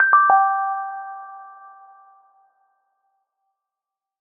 dm_received.ogg